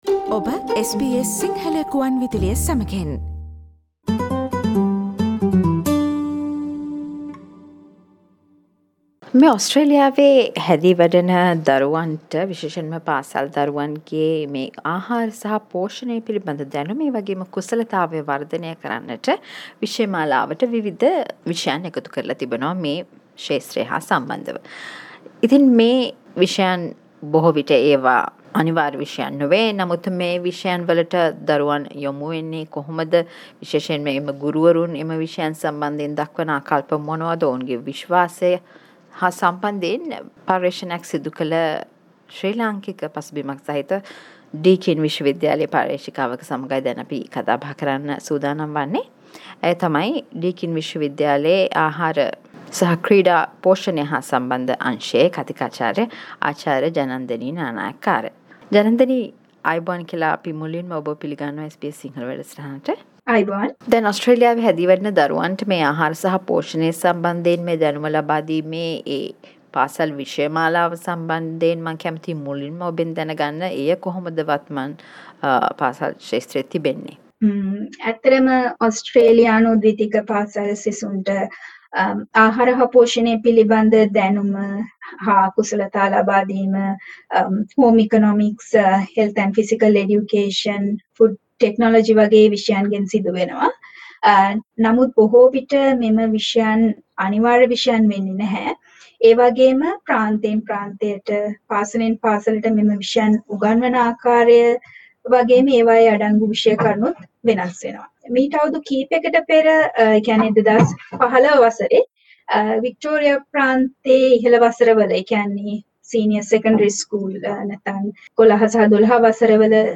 A discussion